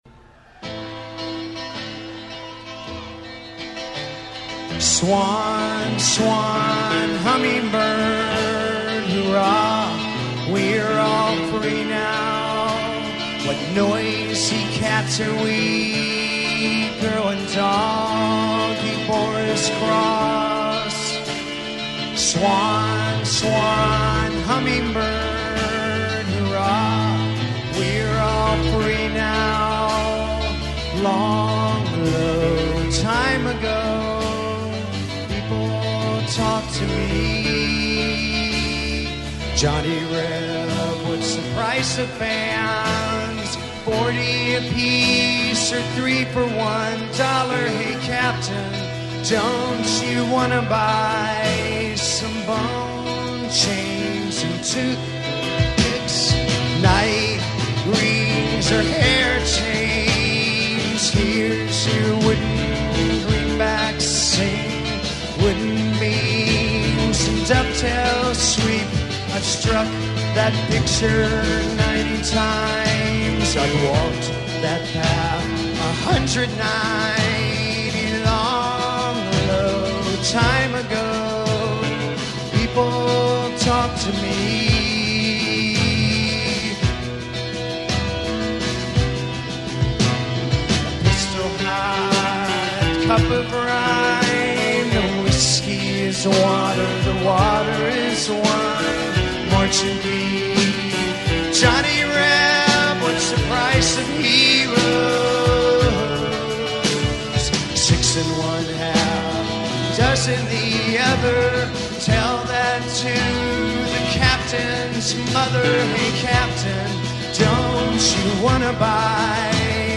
bootleg